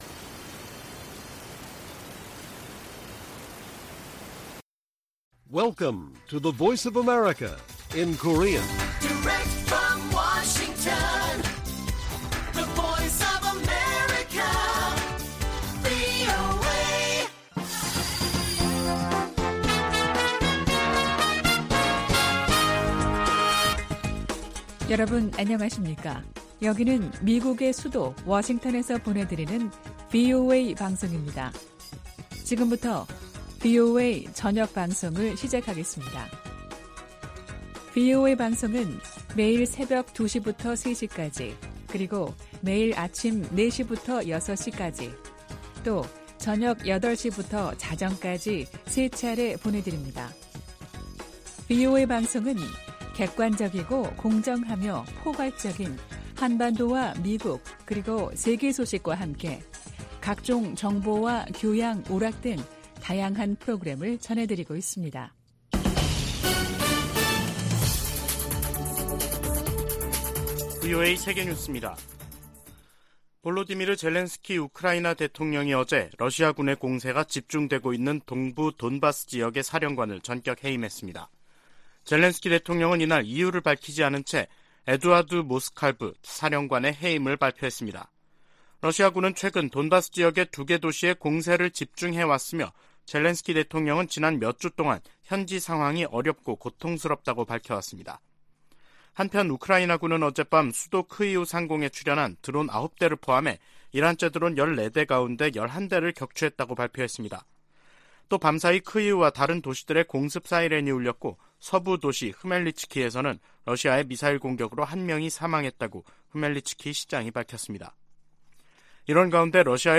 VOA 한국어 간판 뉴스 프로그램 '뉴스 투데이', 2023년 2월 27일 1부 방송입니다. 백악관은 러시아 용병조직 바그너 그룹에 북한이 무기를 지원했다고 거듭 비판했습니다. 미 국방부는 중국이 러시아 지원 카드를 완전히 내려놓지 않았다며 예의주시할 것이라고 밝혔습니다. 남-북한은 유엔총회에서 바그너 그룹에 대한 북한의 무기거래 문제로 설전을 벌였습니다.